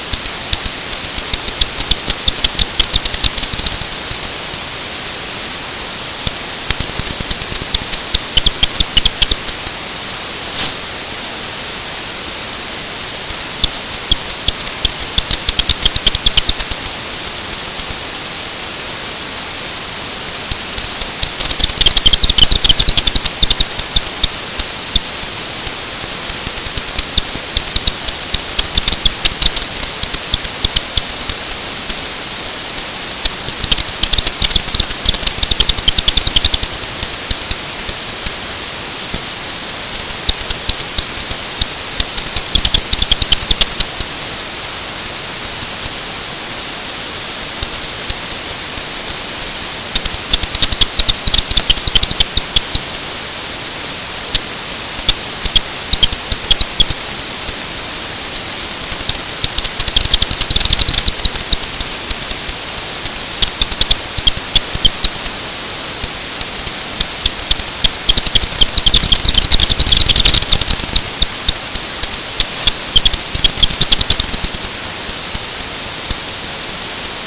ultrasound mic outside window
detail: you can see that THIS signal came in burst’s. Signals can vary.
ultrasound-mic-outside-window-edit.mp3